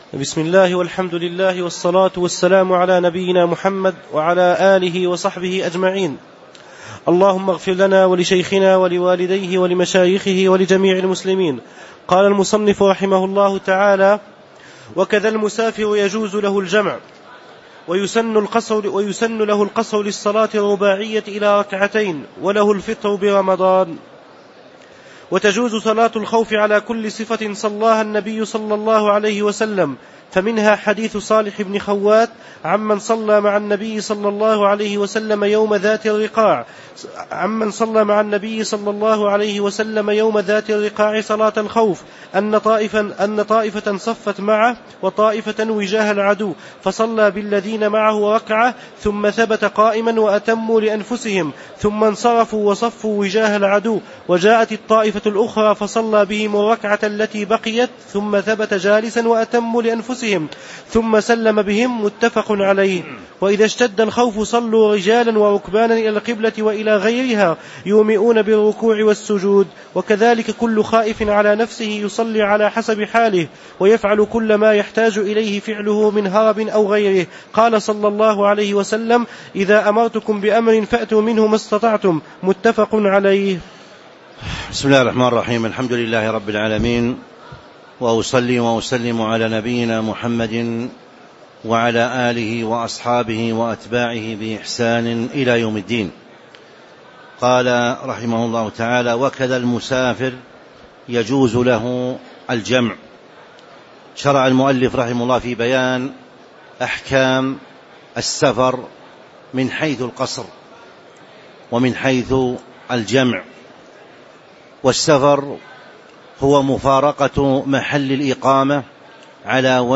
تاريخ النشر ٢٧ رمضان ١٤٤٥ هـ المكان: المسجد النبوي الشيخ